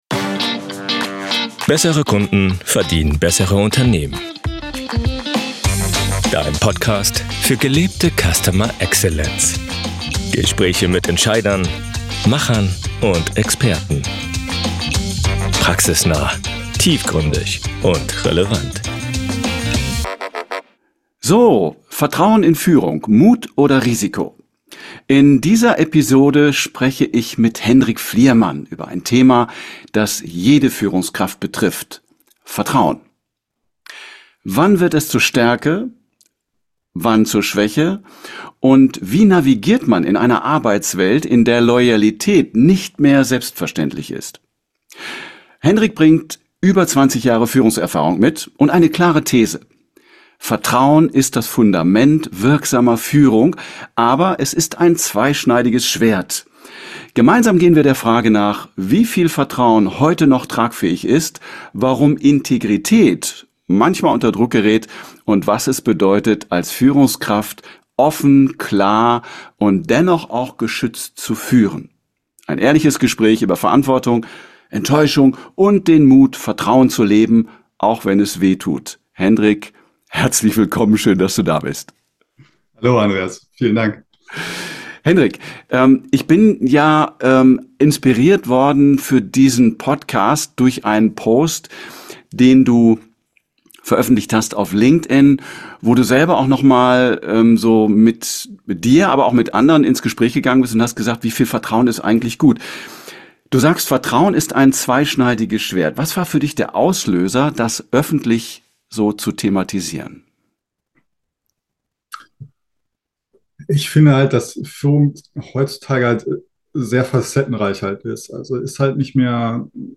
Ein Gespräch über Klarheit, Verantwortung und den Mut, Teams groß werden zu lassen.